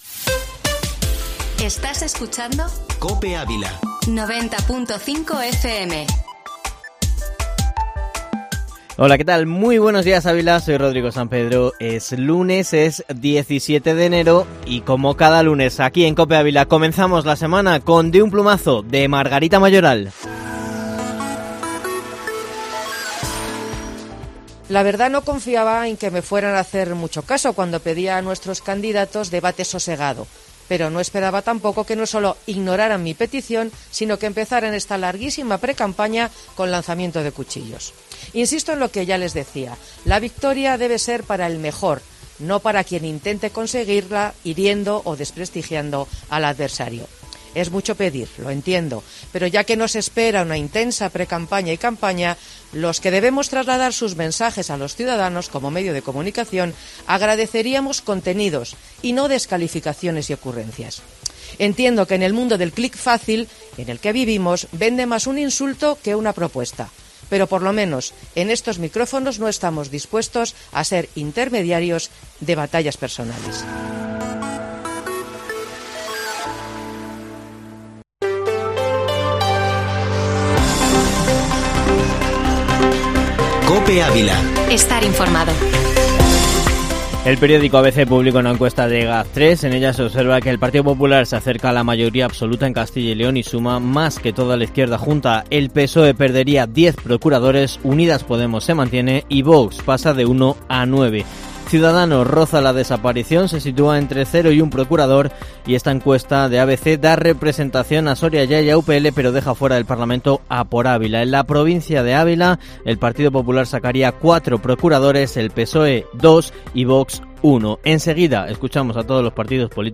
Informativo Matinal Herrera en COPE Ávila-17-enero